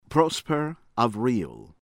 ARISTIDE, JEAN-BERTRAND ZHAH(n)   behr-TRAH(n)   ah-rih-STEED